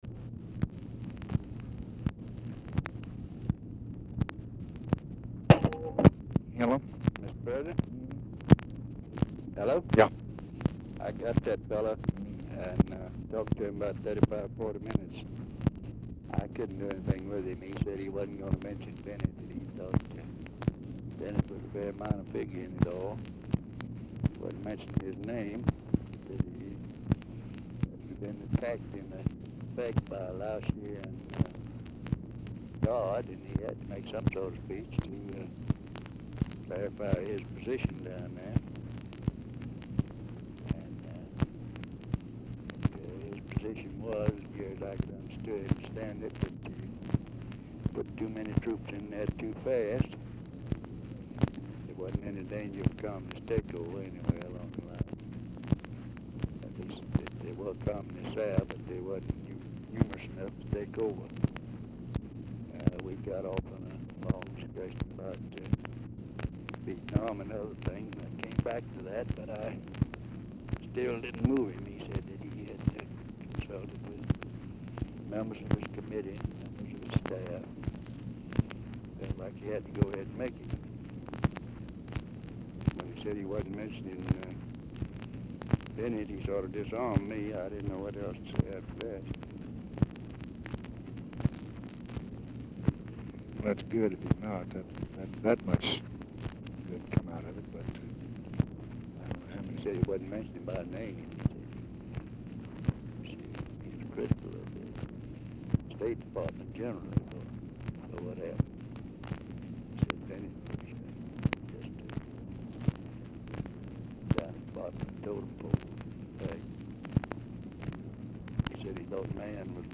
RUSSELL IS DIFFICULT TO HEAR AT TIMES; CONTINUES ON NEXT RECORDING
Format Dictation belt
Specific Item Type Telephone conversation